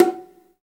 Index of /90_sSampleCDs/Roland - Rhythm Section/PRC_Latin 1/PRC_Conga+Bongo
PRC BONGO 9.wav